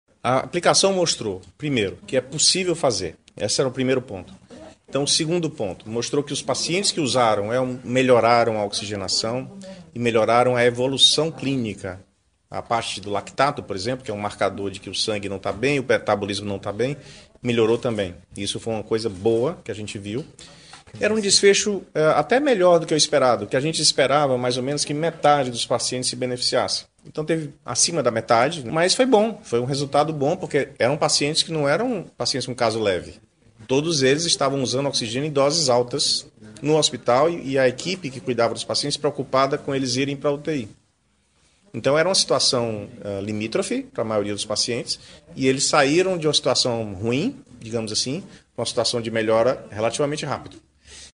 O idealizador do equipamento e superintendente da ESP, Marcelo Alcântara, fala sobre a eficácia do produto.